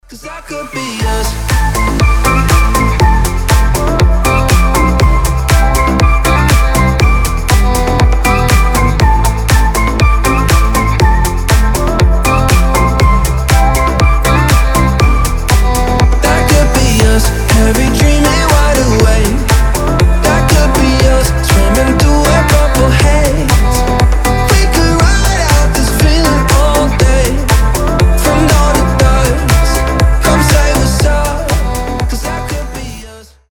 • Качество: 320, Stereo
гитара
свист
легкие